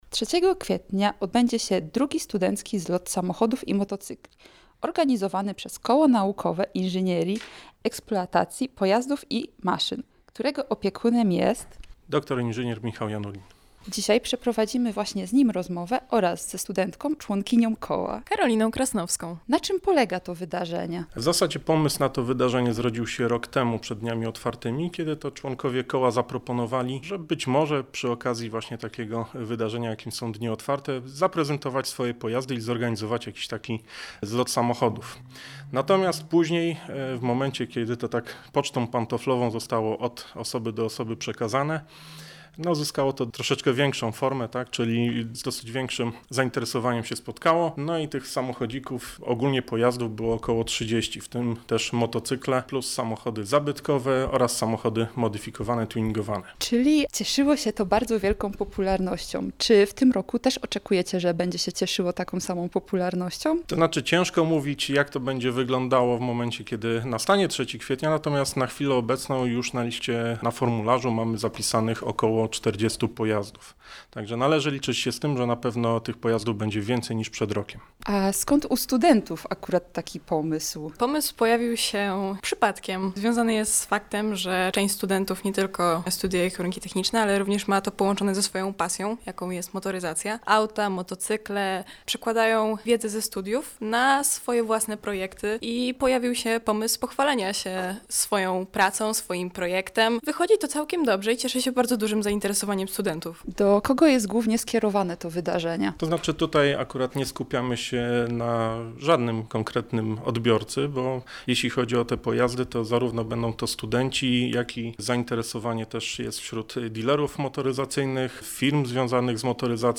Posłuchajcie rozmowy naszej reporterki